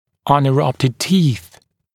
[ˌʌnɪ’rʌptɪd tiːθ][ˌани’раптид ти:с]непрорезавшиеся зубы